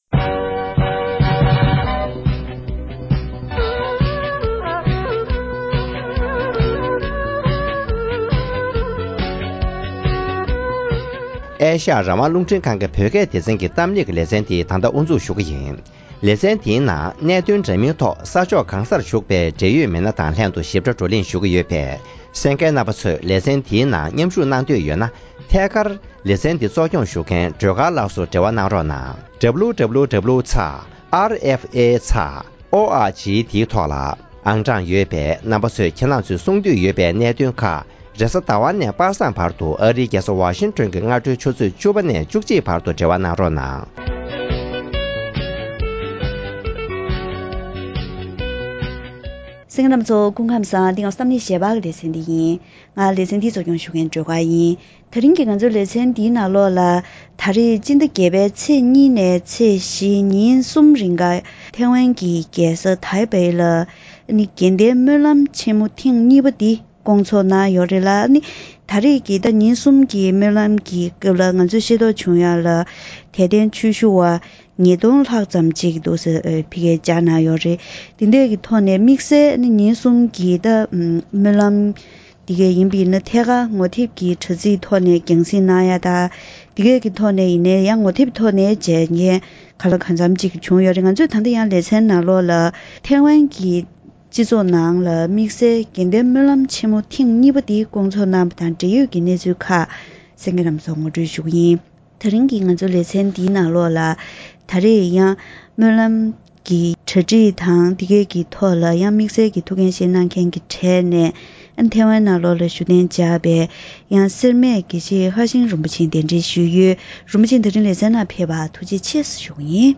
འབྲེལ་ཡོད་ཀྱི་ཞིབ་ཕྲའི་གནས་ཚུལ་སྐོར་ལ་བཀའ་མོལ་ཞུས་པ་ཞིག་གསན་རོགས་གནང་།